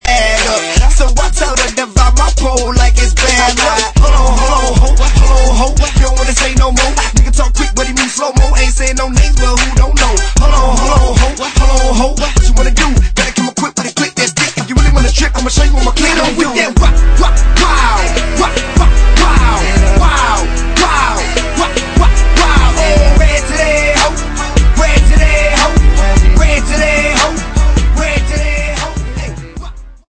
Rap & Hip Hop